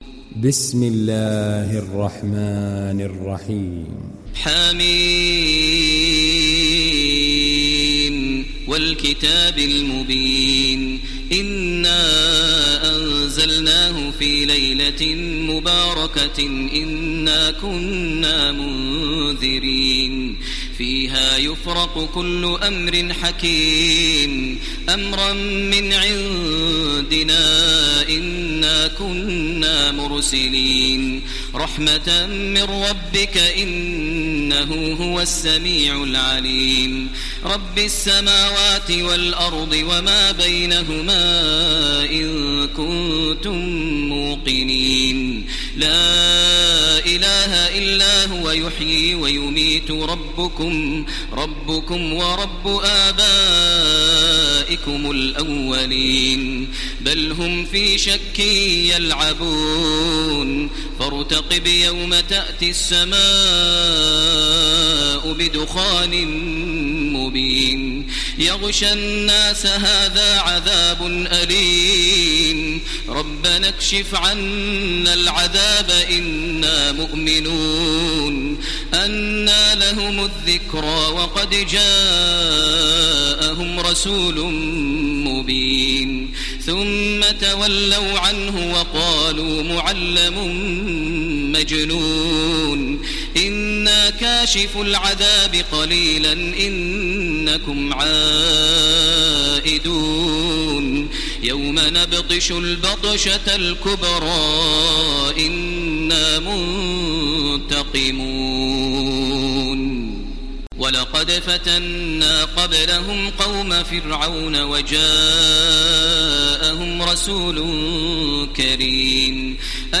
تحميل سورة الدخان mp3 بصوت تراويح الحرم المكي 1430 برواية حفص عن عاصم, تحميل استماع القرآن الكريم على الجوال mp3 كاملا بروابط مباشرة وسريعة
تحميل سورة الدخان تراويح الحرم المكي 1430